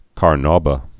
(kär-nôbə, -nou-, -n-)